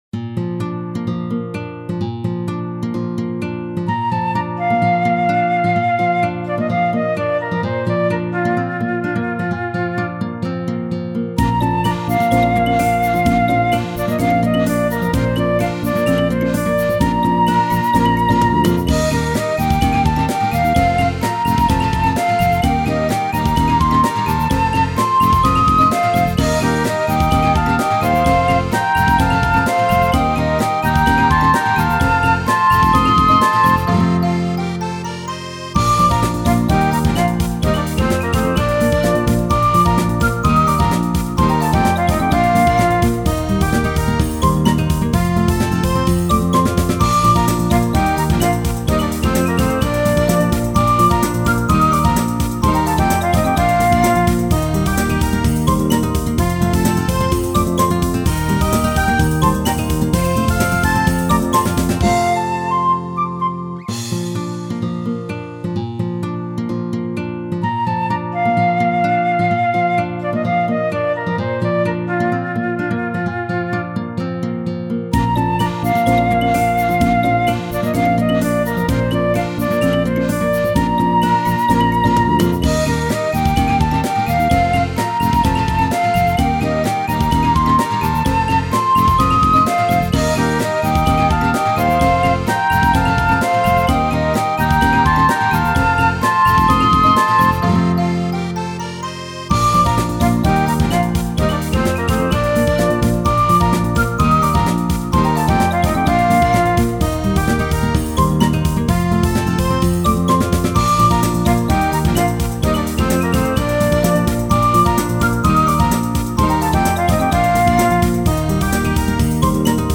ogg(L) 街 にぎやか アップテンポ ポップ
軽快なギターとフルートが爽やかな曲。